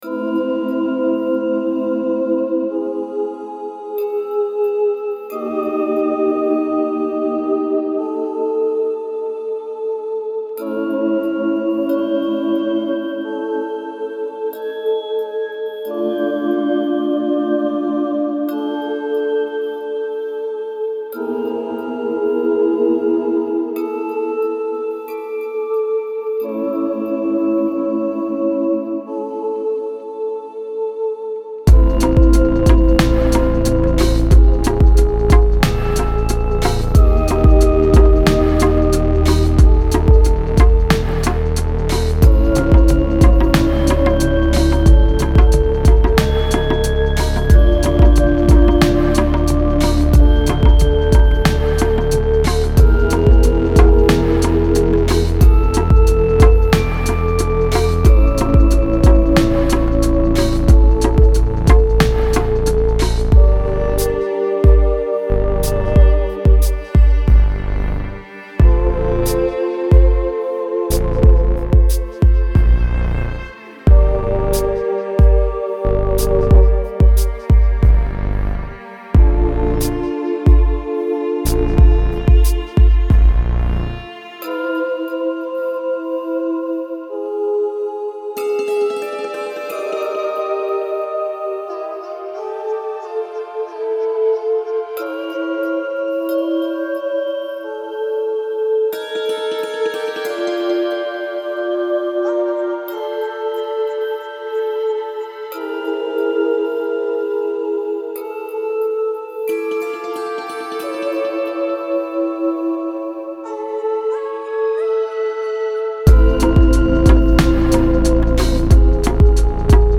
Style Style Ambient, Soundtrack
Mood Mood Calming, Dark, Intense +1 more
Featured Featured Bass, Bells, Cello +3 more
BPM BPM 91
who wanted something both dreamy and ominous!